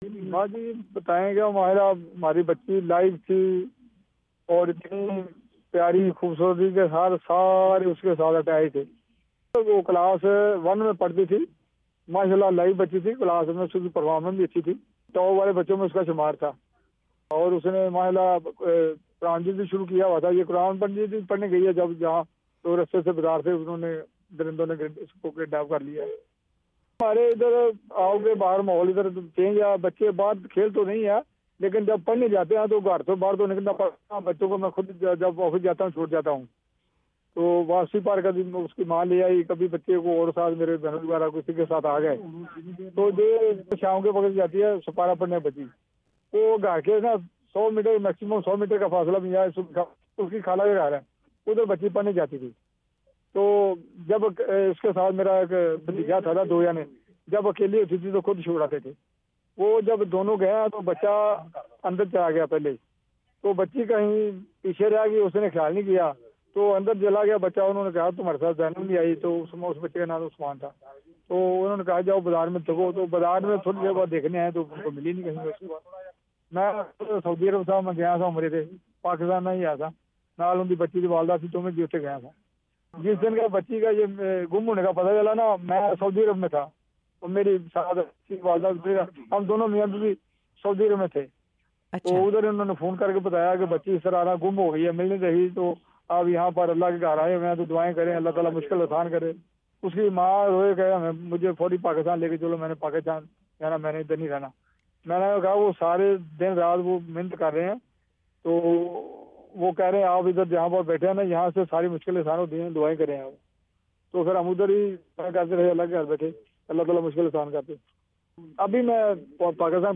انڑویو